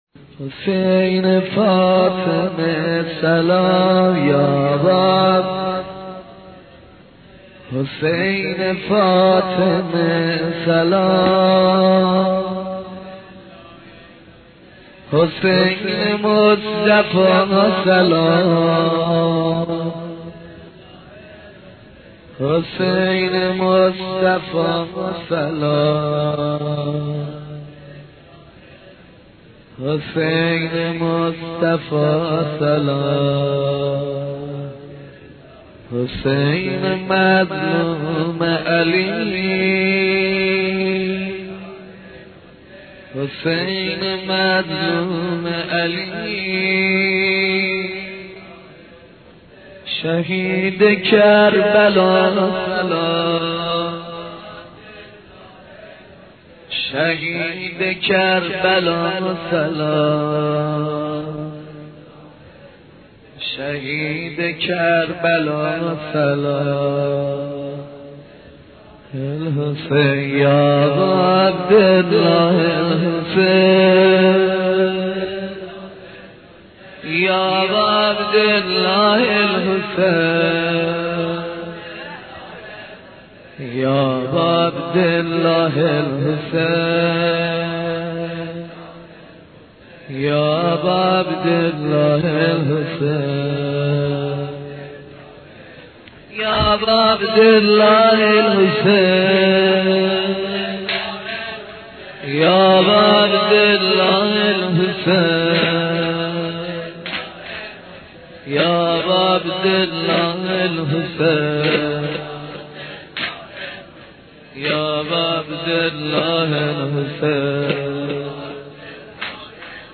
نوحه ماندگار غم نامه حضرت رقیه سلام الله علیها